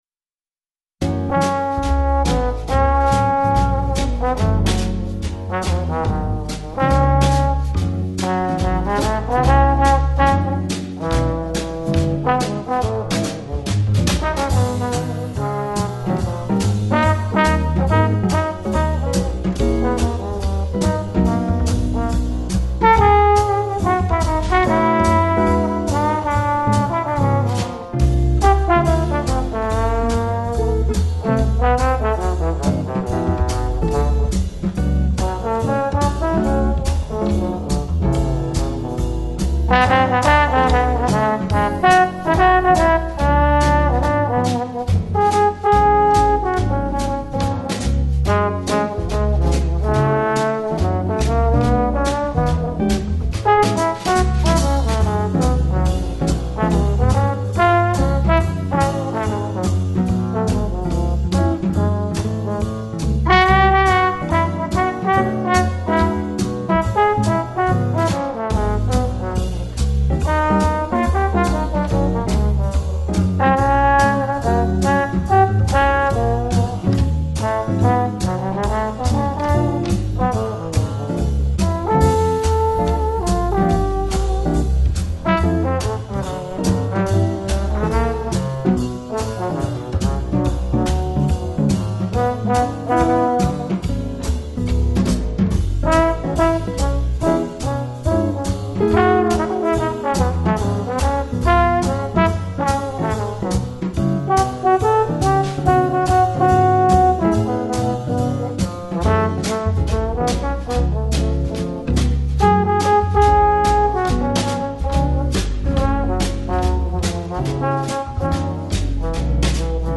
Жанр: Smooth Jazz